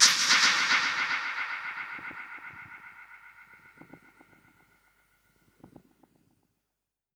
Index of /musicradar/dub-percussion-samples/134bpm
DPFX_PercHit_A_134-04.wav